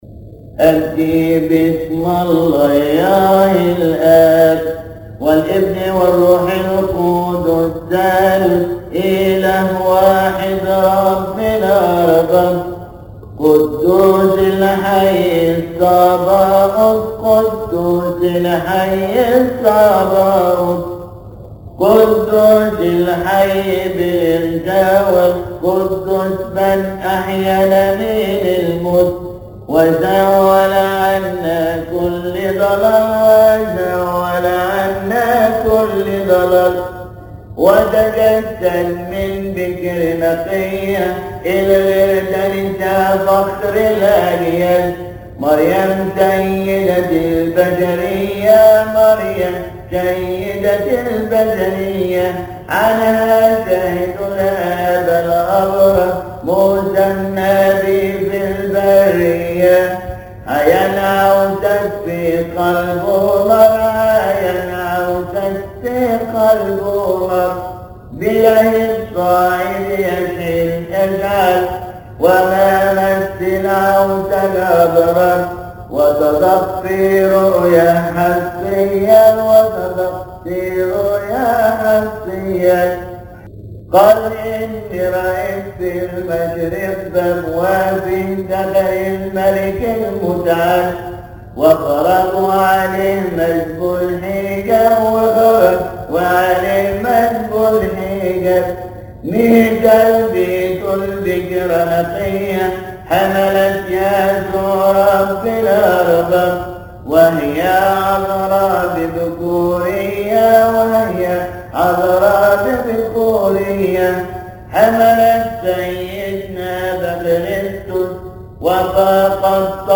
يصلي في تسبحة عشية أحاد شهر كيهك
008.Abdi_Bism_Allah_Elab_-_Arabic_Praise.mp3